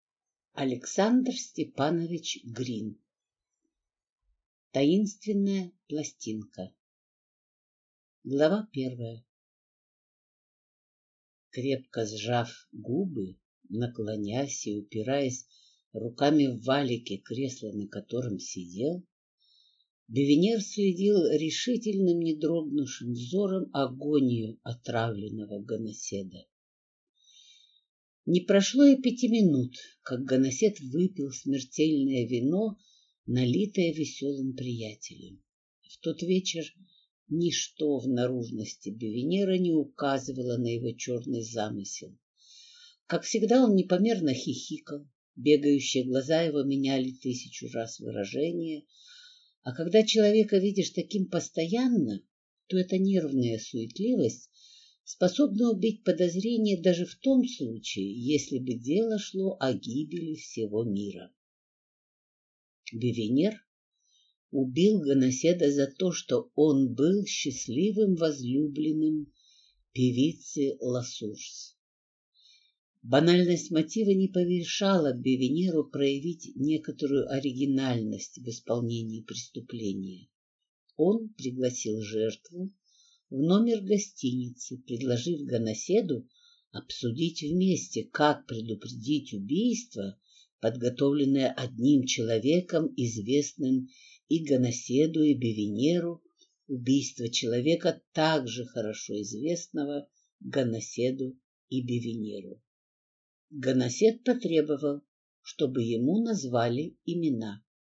Аудиокнига Таинственная пластинка | Библиотека аудиокниг